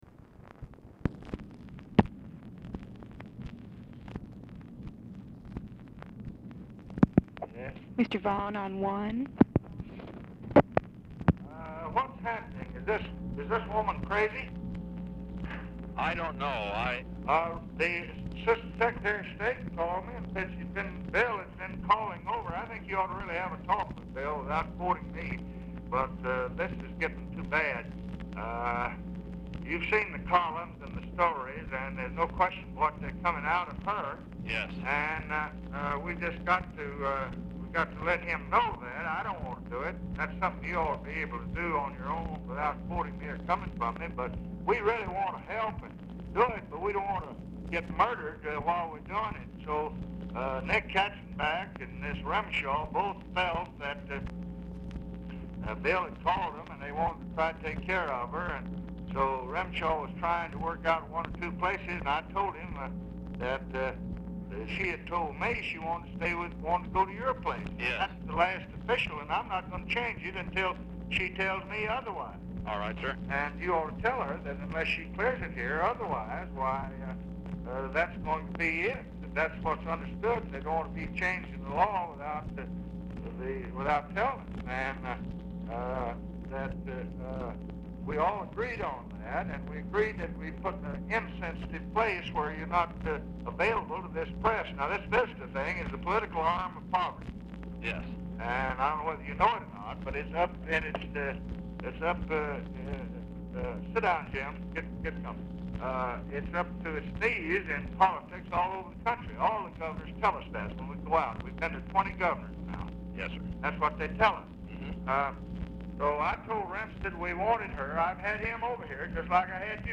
LBJ IS MEETING WITH JAMES FARLEY AT TIME OF CALL AND SPEAKS WITH HIM BRIEFLY; LBJ APPARENTLY ON SPEAKERPHONE AT BEGINNING OF CALL
Format Dictation belt
Specific Item Type Telephone conversation Subject Appointments And Nominations National Politics Press Relations Welfare And War On Poverty